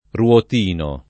ruotino [ r U ot & no ]